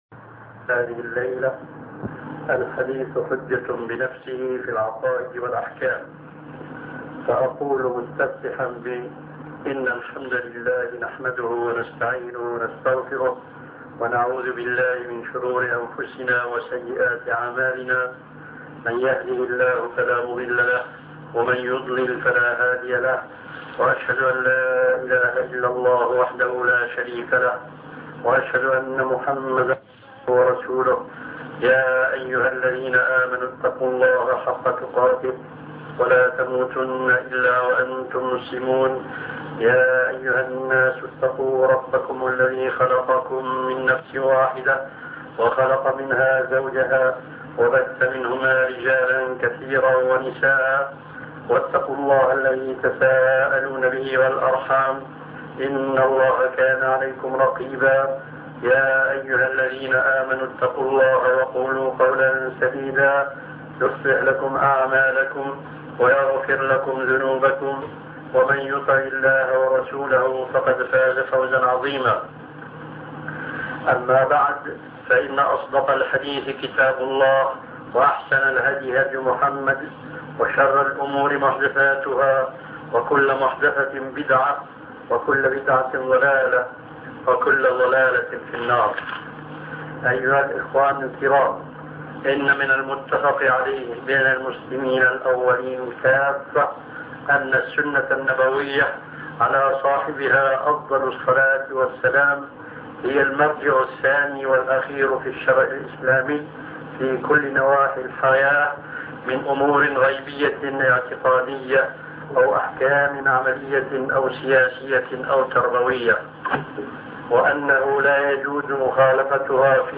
محاضرة الحديث حجة بنفسه في العقائد والأحكام الشيخ محمد ناصر الدين الألباني